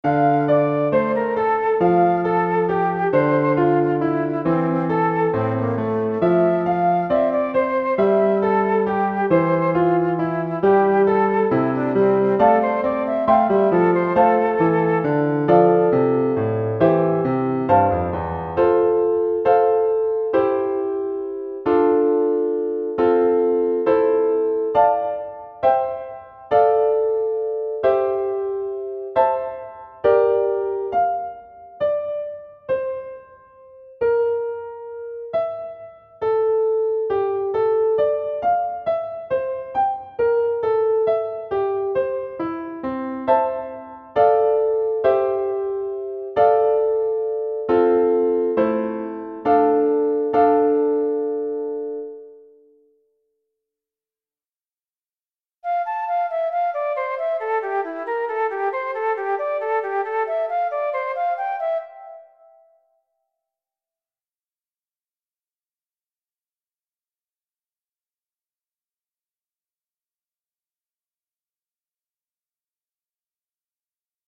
emotional multisegmented piece for flute and piano, unfinished